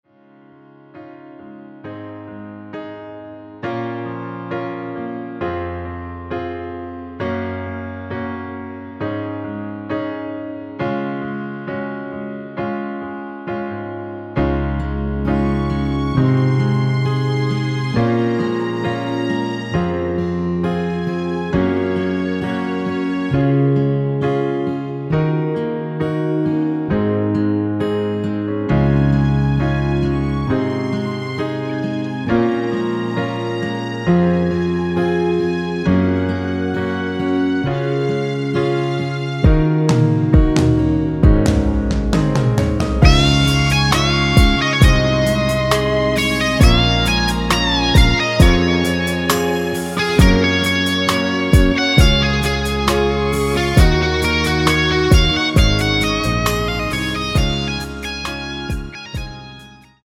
1절후 후렴으로 편곡한 MR (진행 순서는 아래의 가사와 미리듣기 참조 하세요)
엔딩이 페이드 아웃이라 노래 하시기 좋게 엔딩을 만들어 놓았습니다.
Db
앞부분30초, 뒷부분30초씩 편집해서 올려 드리고 있습니다.
중간에 음이 끈어지고 다시 나오는 이유는